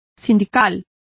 Also find out how sindical is pronounced correctly.